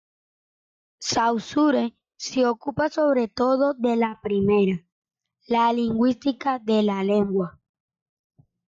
Pronounced as (IPA) /ˈlenɡwa/